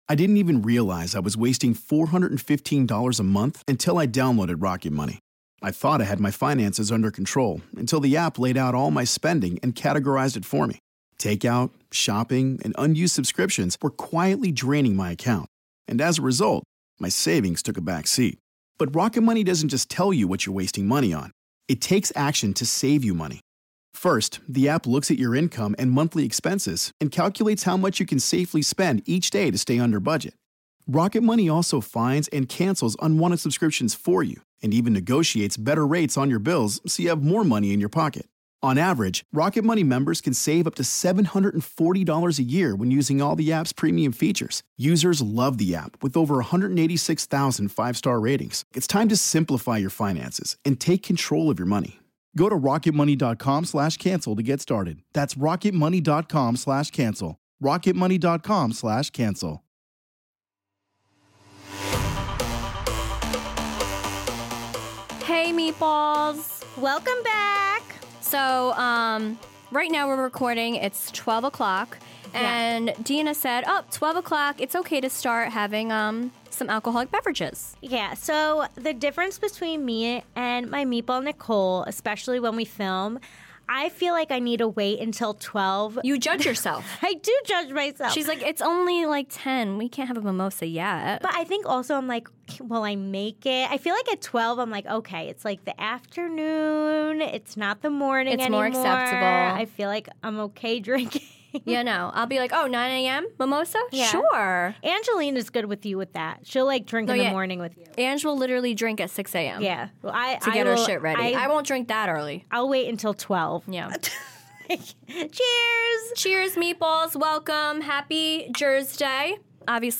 Headliner Embed Embed code See more options Share Facebook X Subscribe In this chaotic episode, the ladies answer some fan questions and have some in-studio company guests!